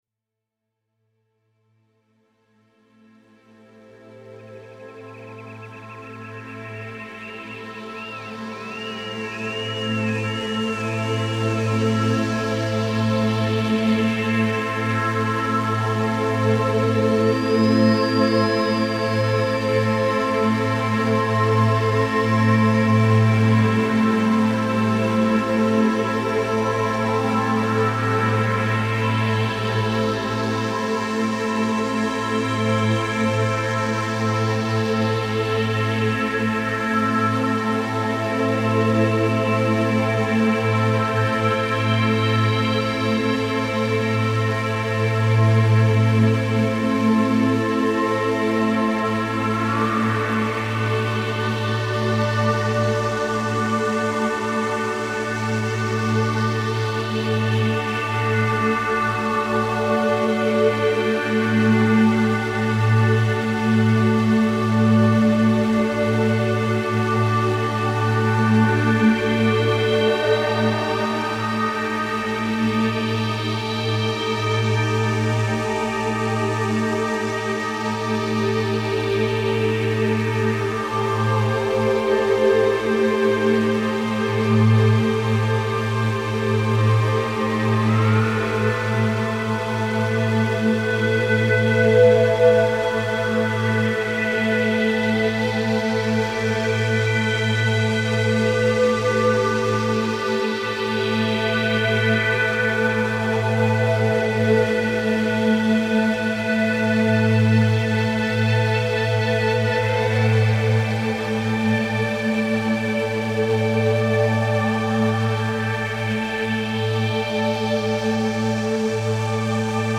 experimental kosmische band